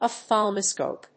音節oph・thal・mo・scope 発音記号・読み方
/ɑfθˈælməskòʊp(米国英語), ɔfθˈælməsk`əʊp(英国英語)/